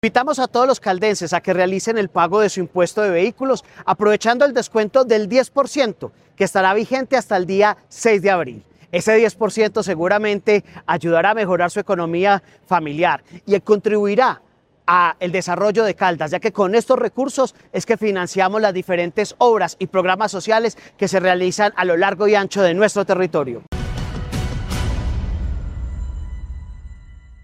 Secretario de Hacienda de Caldas, John Alexander Alzate Quiceno.
John-Alexander-Alzate-Quiceno-Impuesto-Vehicular.mp3